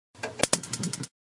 描述：单击单击单击单击它是什么
Tag: 秘密 点击 声音